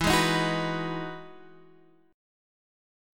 E Major 9th
EM9 chord {0 2 1 1 0 2} chord